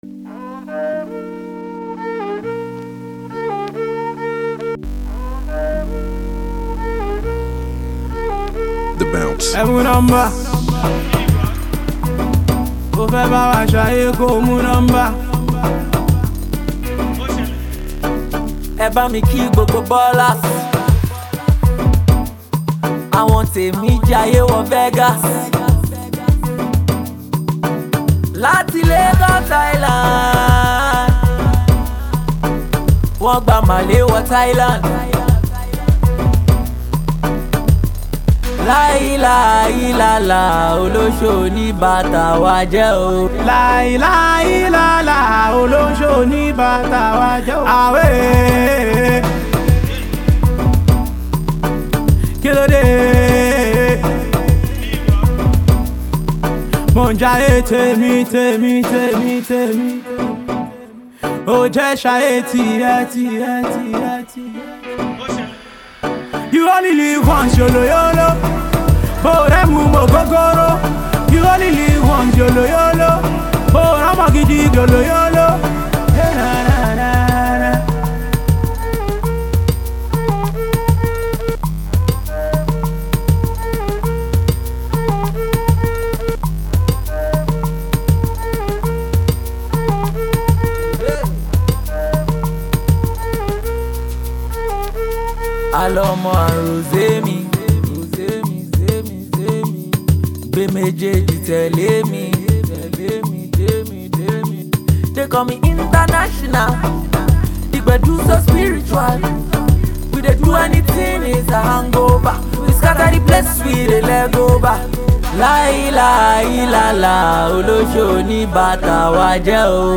Pop, Yoruba Music
Mellow Pop song